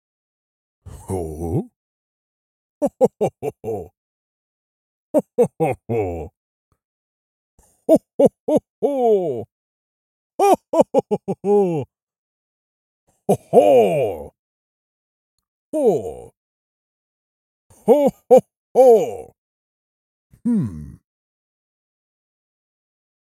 Звуки Санта-Клауса
Смех зимнего волшебника Рождества